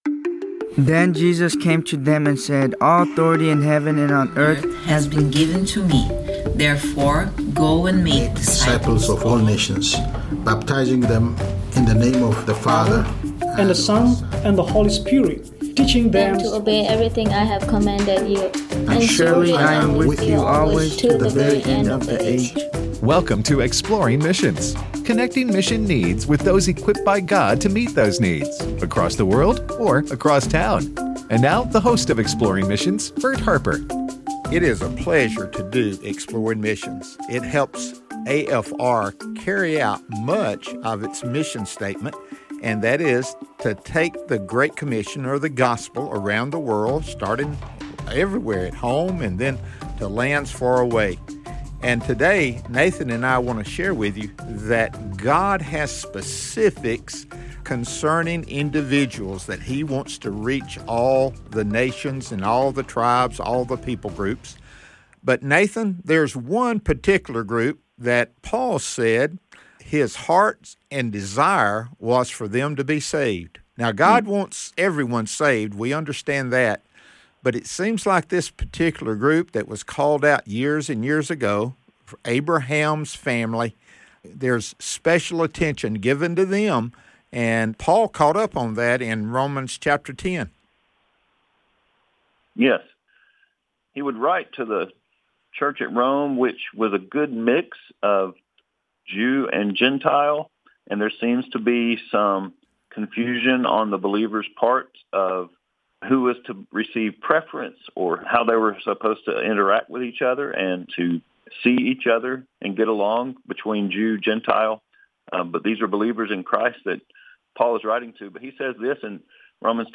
Ministry to Israel: A Conversation